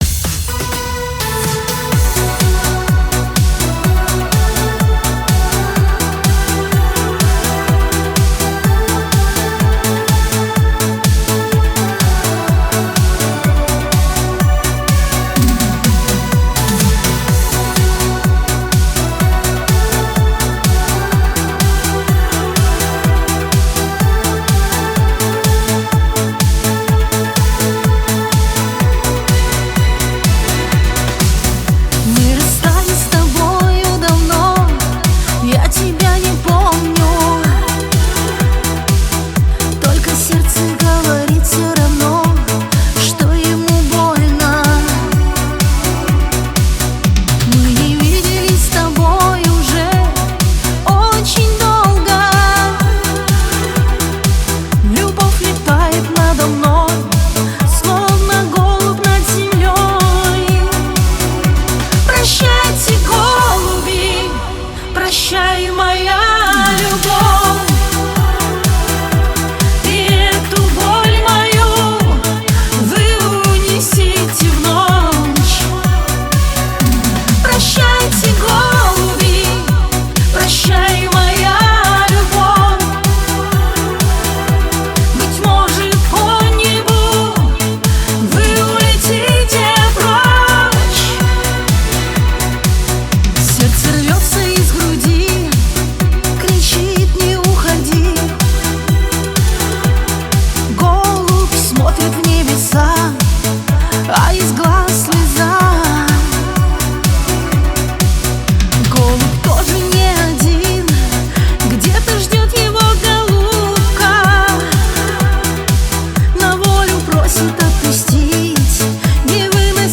Шансон песни
шансон музыка